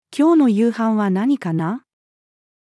今日の夕飯は何かな？-female.mp3